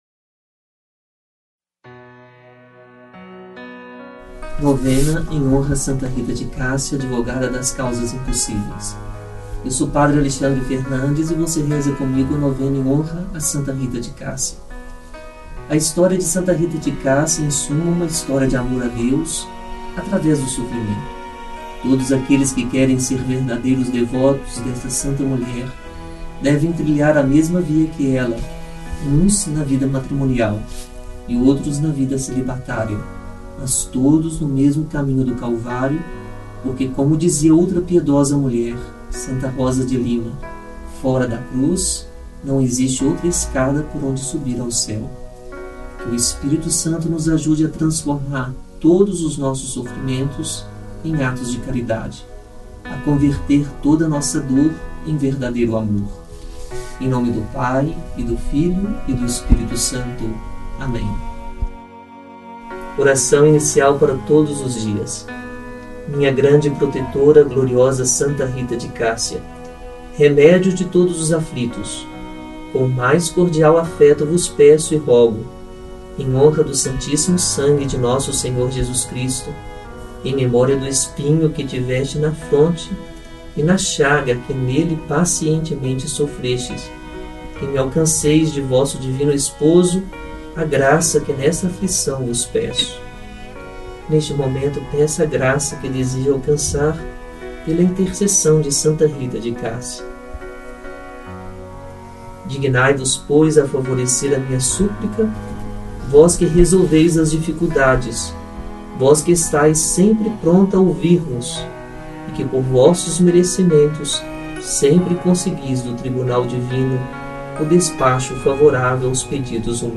DIA 8 – NOVENA A SANTA RITA DE CASSIA